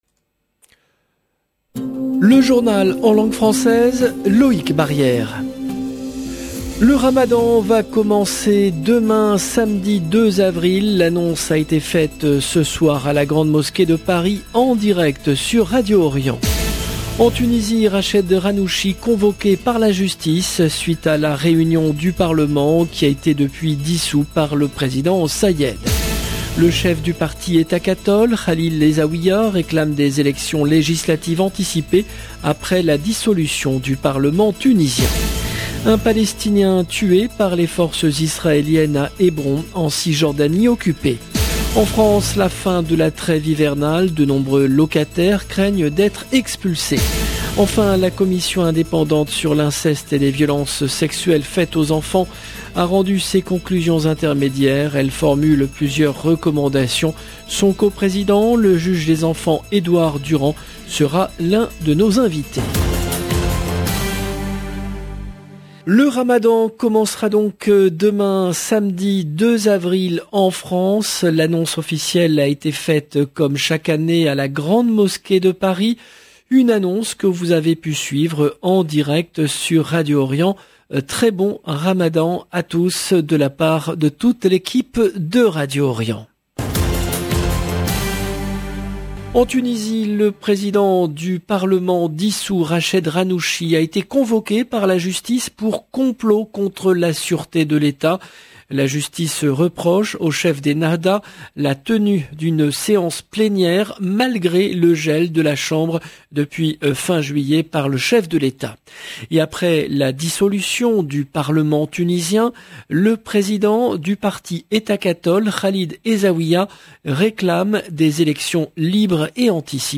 Entretien avec son co-président, le juge des enfants Edouard Durand. 0:00 18 min 11 sec